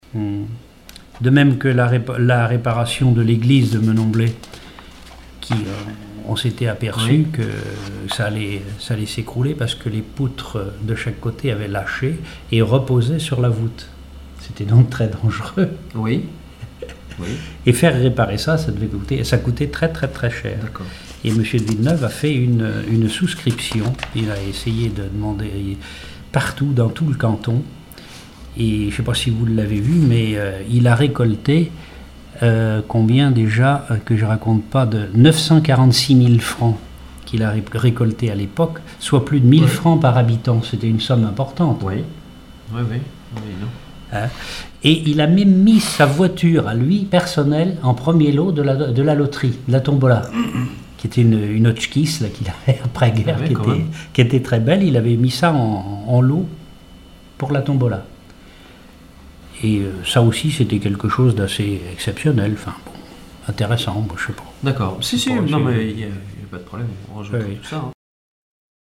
Témoignages ethnologiques et historiques
Catégorie Témoignage